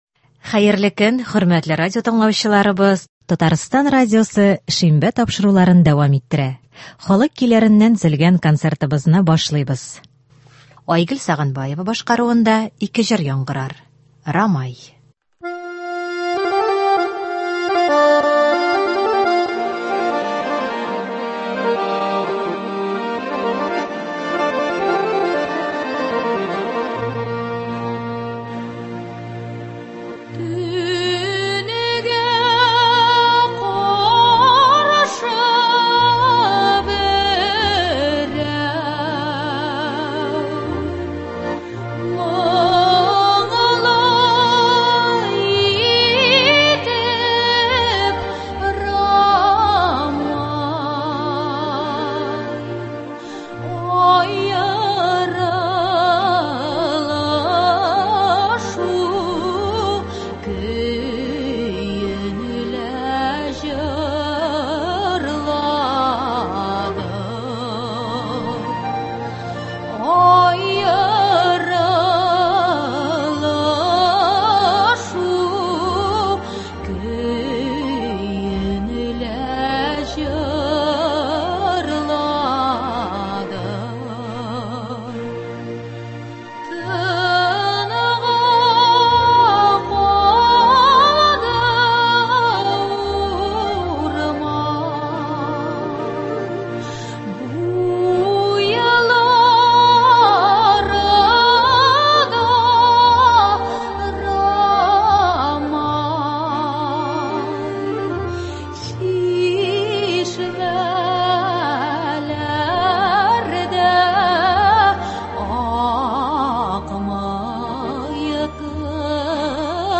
Татар халык җырлары (15.05.21)
Бүген без сезнең игътибарга радио фондында сакланган җырлардан төзелгән концерт тыңларга тәкъдим итәбез.